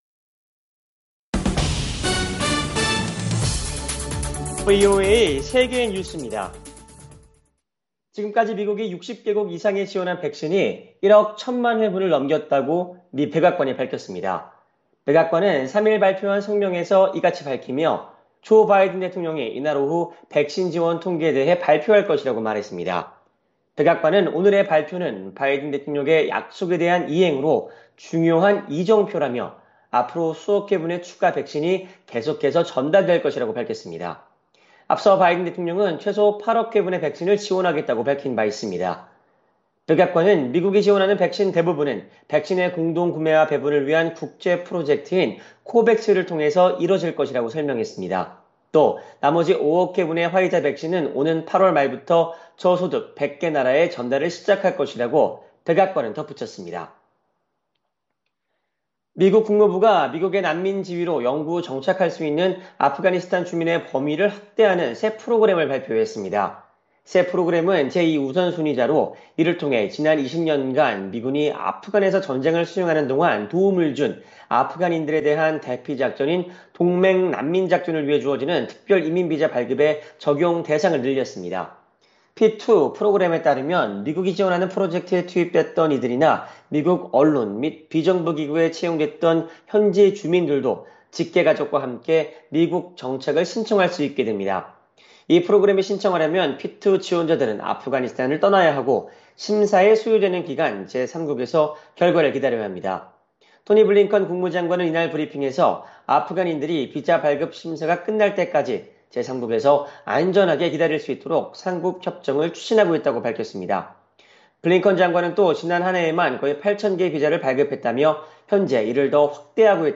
VOA 한국어 간판 뉴스 프로그램 '뉴스 투데이', 2021년 8월 3일 3부 방송입니다. 미국 국무부는 미-한 연합군사훈련 중단을 압박한 북한 김여정 부부장의 담화와 관련해 확고한 방어태세를 유지하겠다는 의지를 거듭 강조했습니다. 1994년 미-북 ‘제네바 기본합의’를 끌어냈던 로버트 갈루치 전 미 국무부 북핵특사가 당시 협상에서 인권 문제를 제기하지 않은 건 잘못된 결정이었다고 밝혔습니다. 북한의 표현의 자유가 전 세계 최악이라고 국제 인권단체가 밝혔습니다.